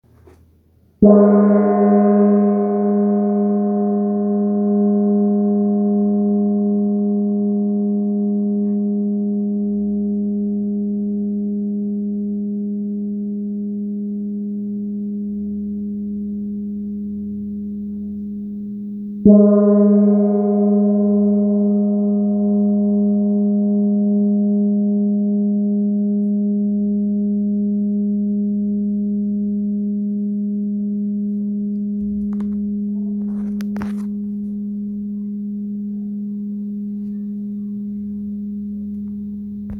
Gong, Tibetan Handmade, Wind Gong, Flat Gong
Material Bronze
Unlike traditional gongs with upturned rims, the wind gong lacks a pronounced cylindrical shape, resulting in unobstructed vibrations that create a deep and immersive sound with a wide range of tonal qualities.